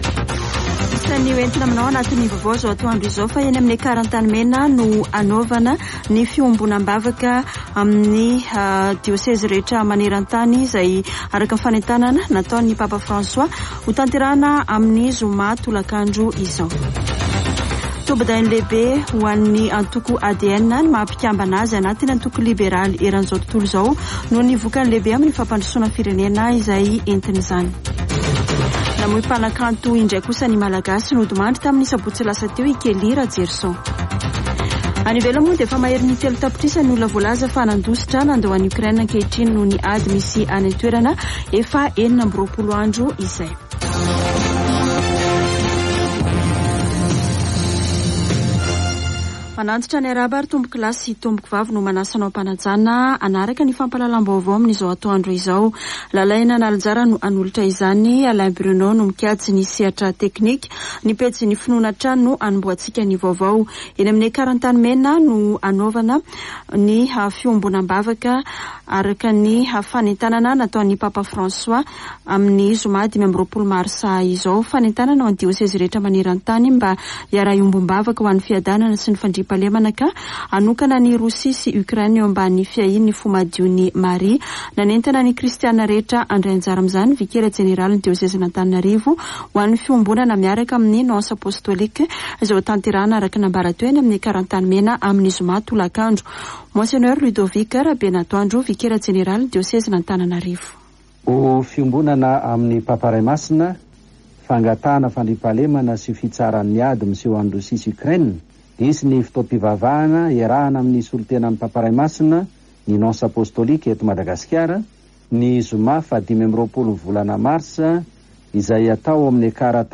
[Vaovao antoandro] Alatsinainy 21 marsa 2022